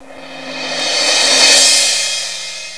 Tubelec RiseCymbal.Drum.wav